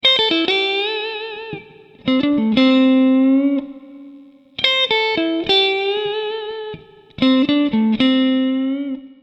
Fraseggio blues 07
Due terzine che risolvono su di un bending finale ognuna.